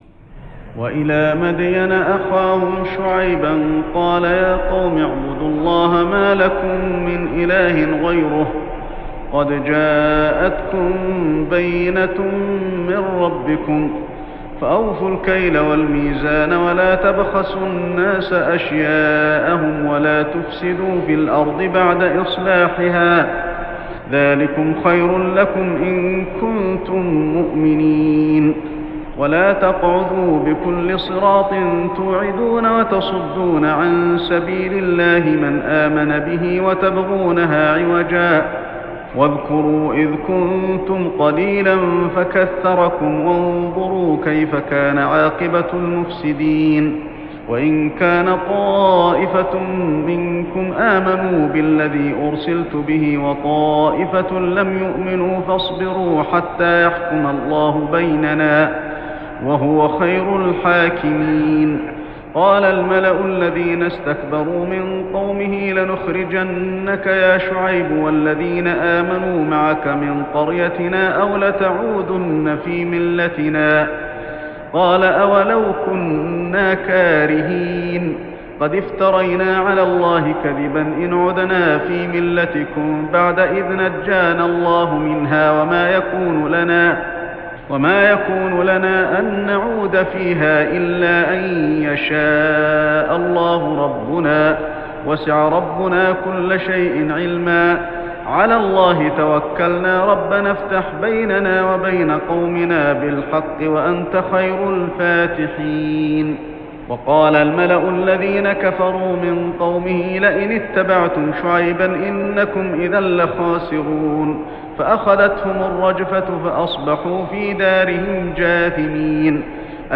صلاة التراويح ليلة 9-9-1408هـ سورتي الأعراف 85-206 و الأنفال 1-40 | Tarawih prayer Surah An-Nisa and Al-Ma'idah > تراويح الحرم المكي عام 1408 🕋 > التراويح - تلاوات الحرمين